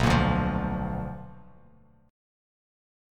BmM7b5 chord